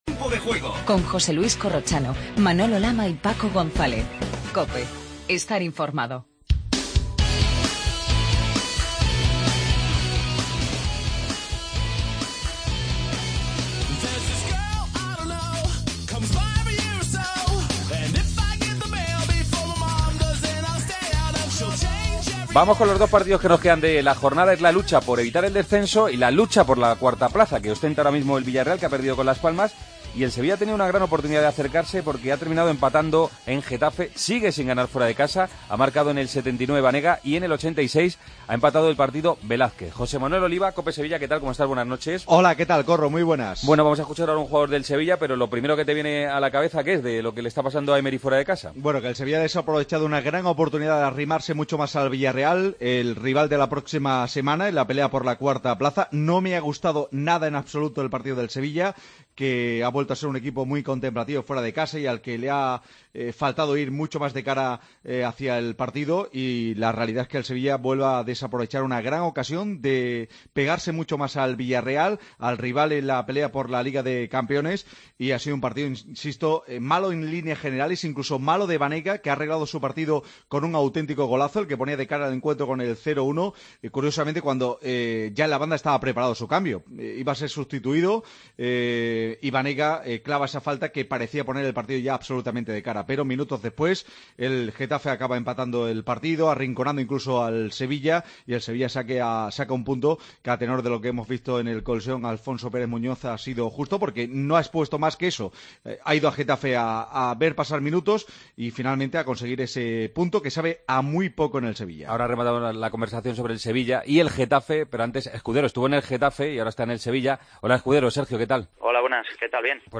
Entrevista a Ander Mirambell.